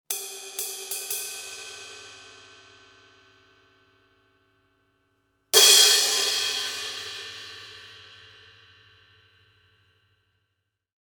- und zwei Paiste 2000 Crashes in 16“,
Soundfile (nicht extra abgedämpft, Sound entstand durch den tieferen Einriß!):